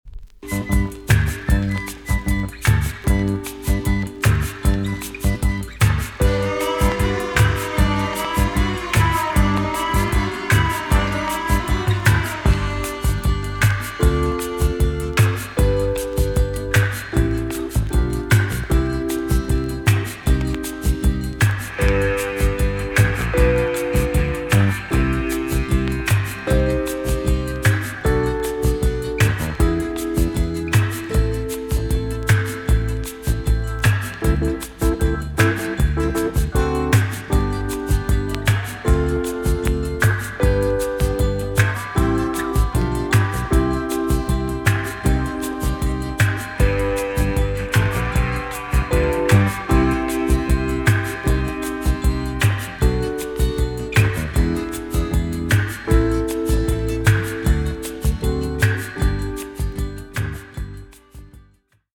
TOP >JAMAICAN SOUL & etc
B.SIDE Version
EX-~VG+ 少し軽いチリノイズが入りますが良好です。